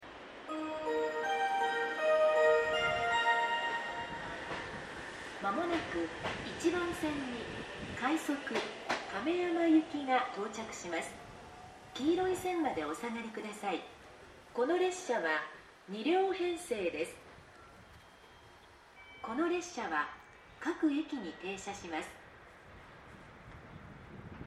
この駅では接近放送が設置されています。
接近放送快速　亀山行き接近放送です。